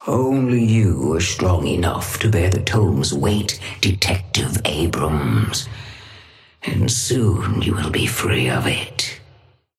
Patron_female_ally_atlas_start_04.mp3